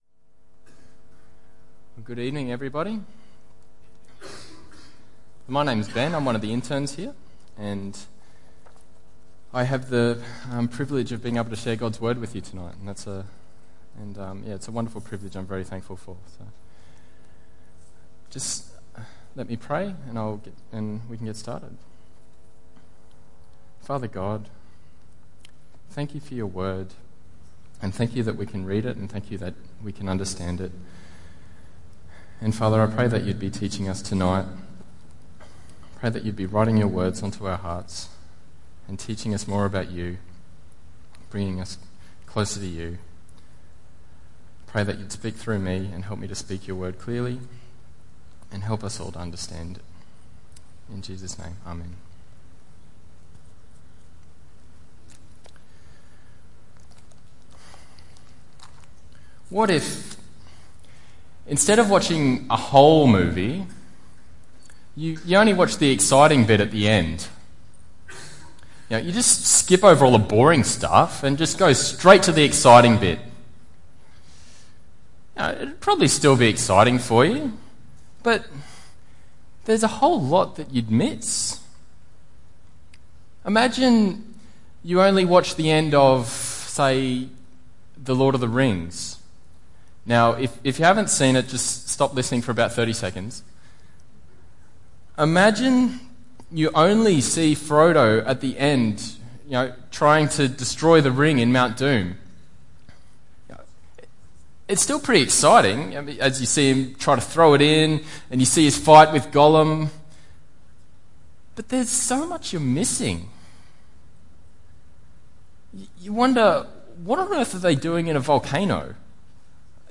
Guest Speaker
Jeremiah 31:3-6 Tagged with Sunday Evening